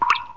DRIP3.mp3